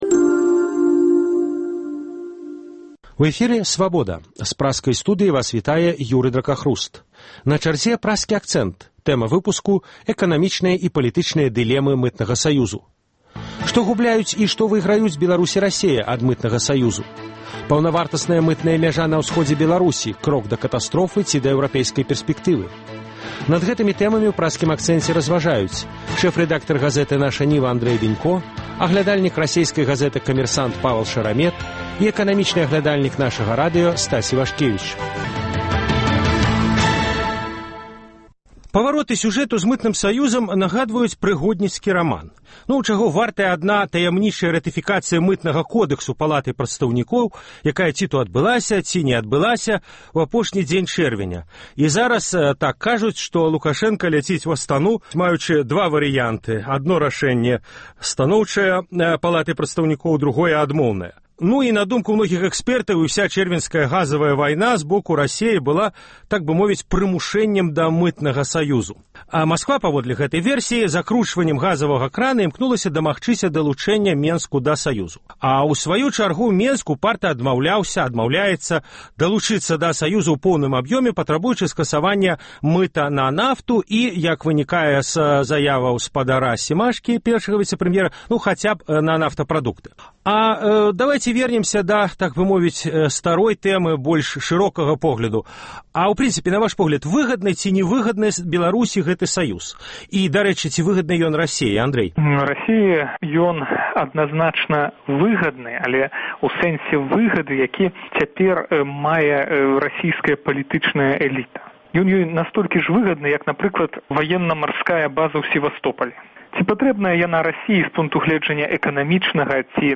Круглы стол аналітыкаў, абмеркаваньне галоўных падзеяў тыдня. Што губляюць і што выйграюць Беларусь і Расея ад Мытнага саюзу? Паўнавартасная мытная мяжа на ўсходзе Беларусі – крок да катастрофы ці да эўрапейскай пэрспэктывы?